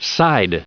side_en-us_recite_stardict.mp3